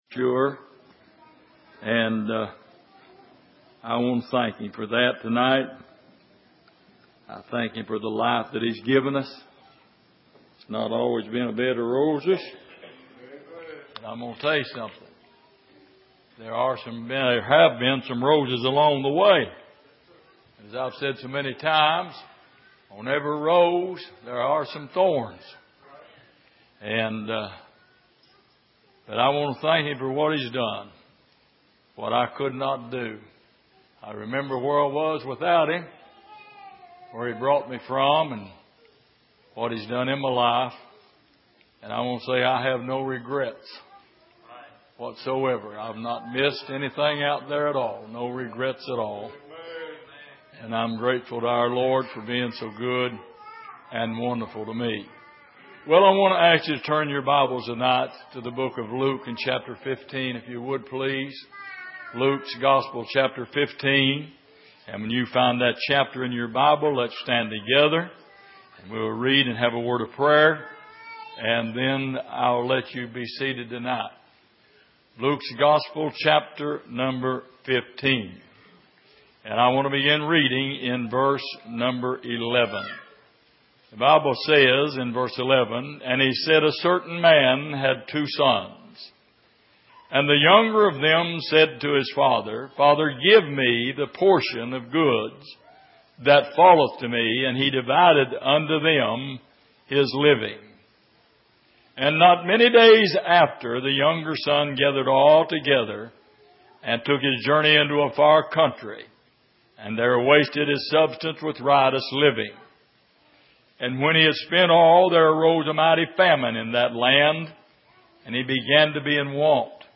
Passage: Luke 15:11-22 Service: Sunday Evening